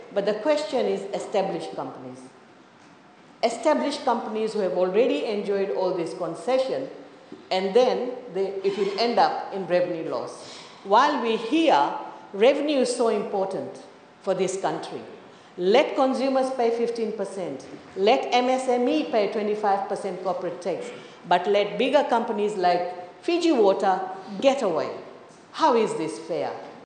Parliament
During the debate, opposition Member of Parliament Premila Kumar says the water bottling companies are actually getting away with the removal of corporate tax and an increase in the threshold for water extraction.